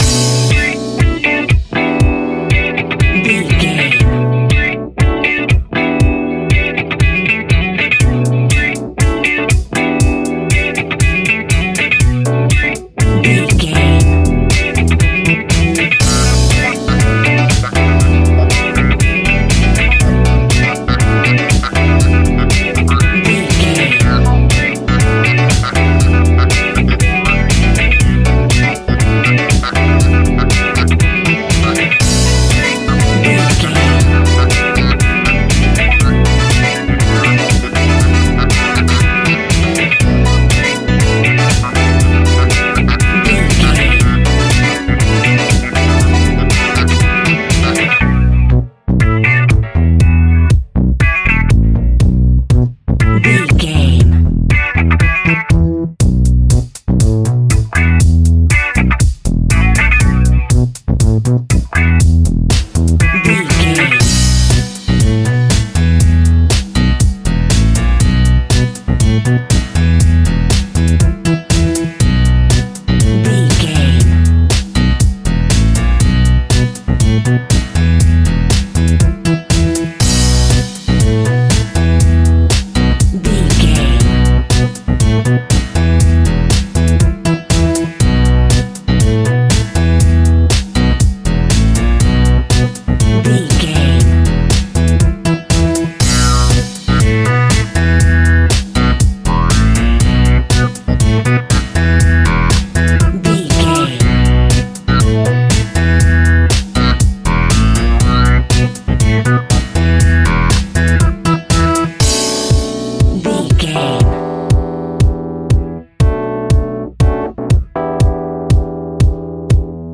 Aeolian/Minor
groovy
futuristic
hypnotic
uplifting
drum machine
synthesiser
electric guitar
electric piano
funky house
disco house
electronic funk
energetic
upbeat
synth leads
Synth Pads
synth bass